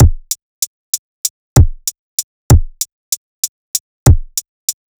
FK096BEAT2-L.wav